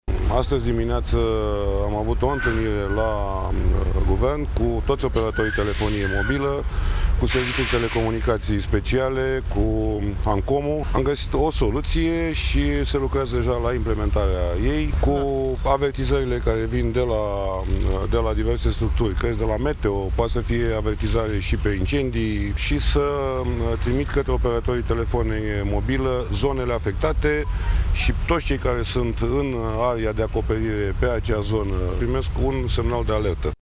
Declarația a fost făcută în urmă cu puțin timp de premierul Mihai Tudose care s-a deplasat la Timișoara pentru a participa la Comandamentul de Urgență împreună cu ministru de Interne, Carmen Dan.